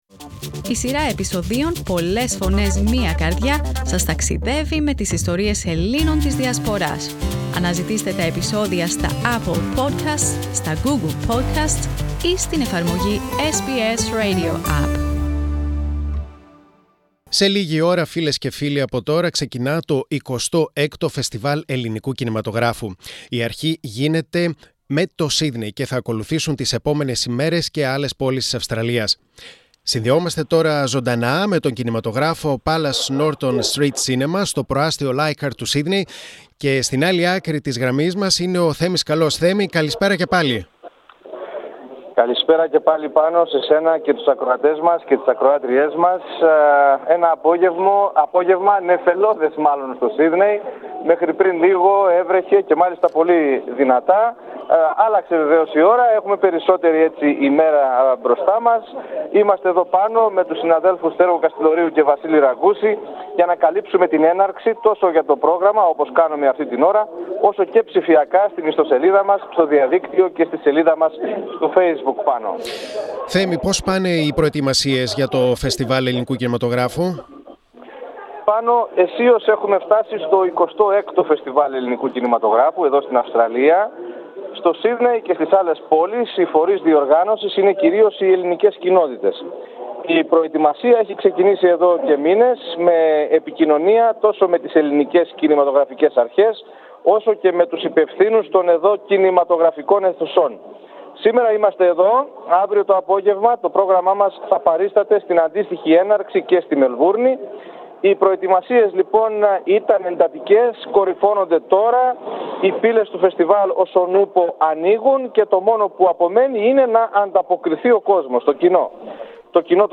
Για να ακούσετε την χθεσινή ανταπόκριση από Palace Norton Street Cinema πατήστε στην κεντρική εικόνα.